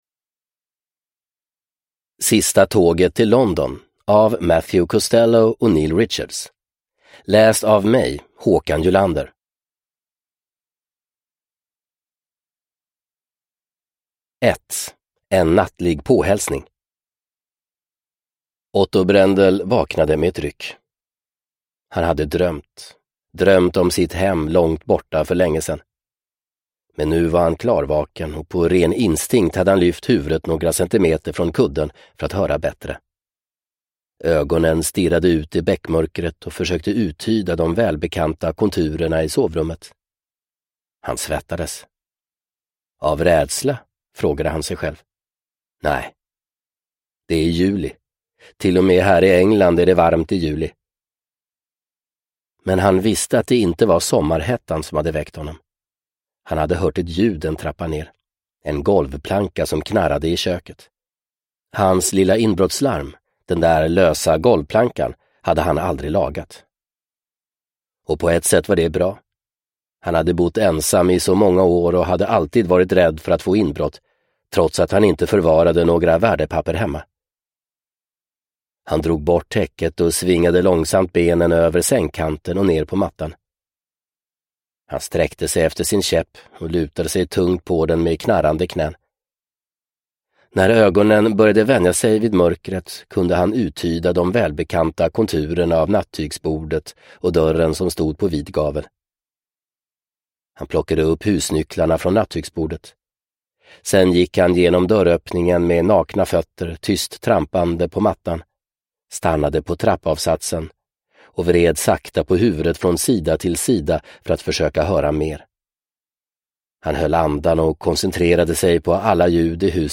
Sista tåget till London – Ljudbok – Laddas ner